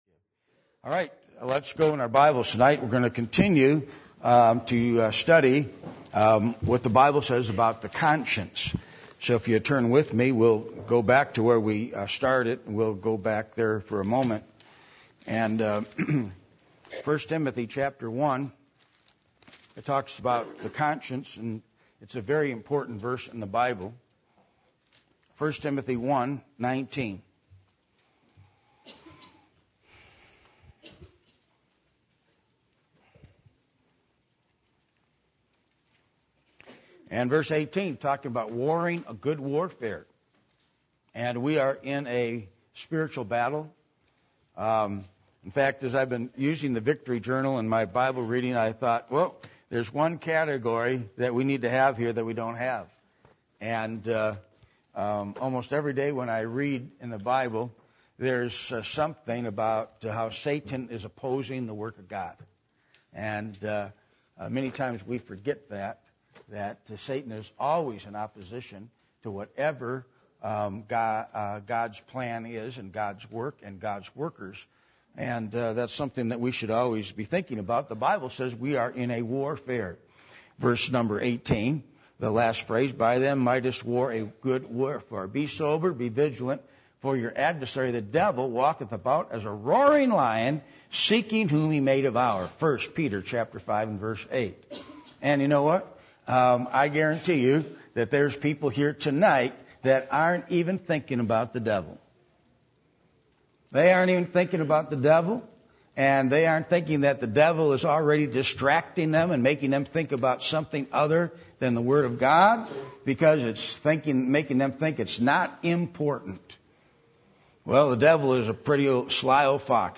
Passage: 1 Timothy 1:19 Service Type: Midweek Meeting %todo_render% « Where Has Sacredness Gone?